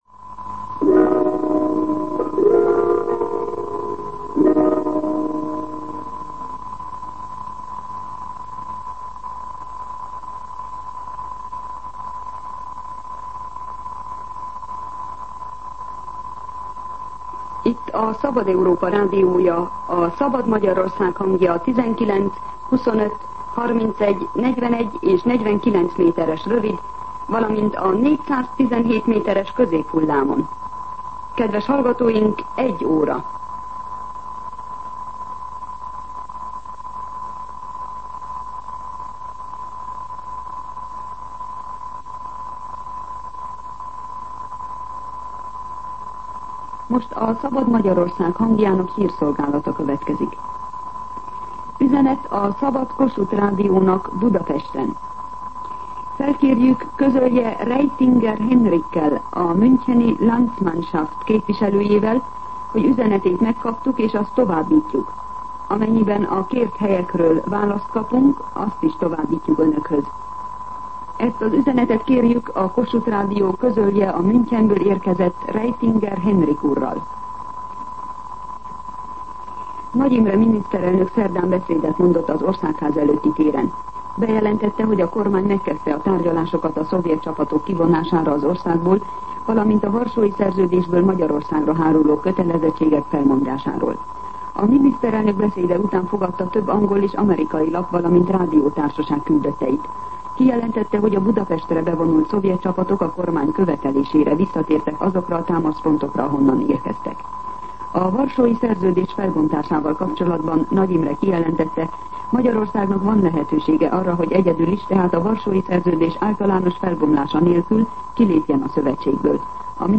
01:00 óra. Hírszolgálat